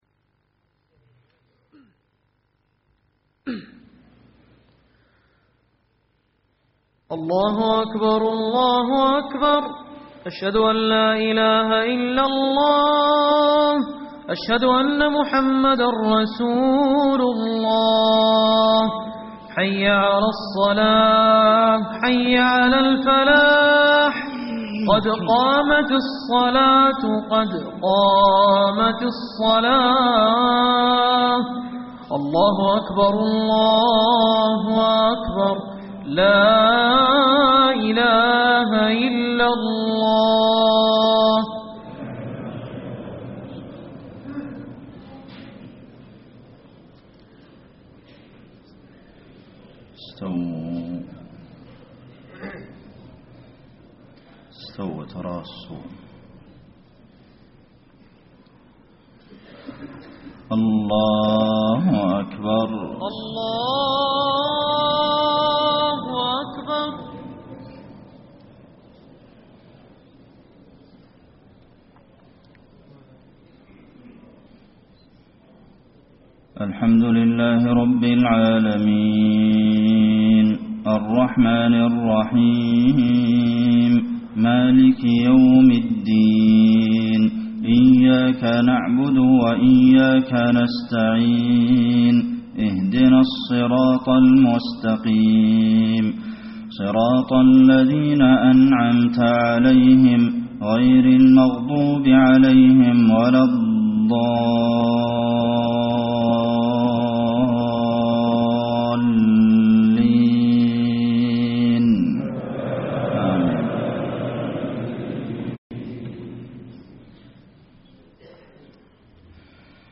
صلاة الفجر 1-8-1434 من سورة البقرة > 1434 🕌 > الفروض - تلاوات الحرمين